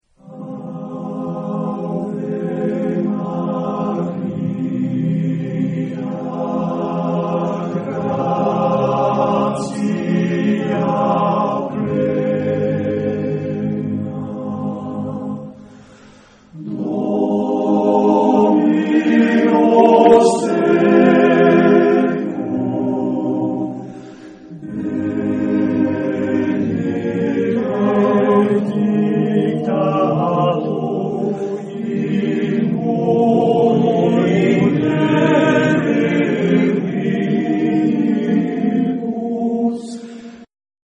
Genre-Style-Forme : Sacré ; Motet
Type de choeur : SATB  (4 voix mixtes )
Instruments : Orgue (1)
Tonalité : fa majeur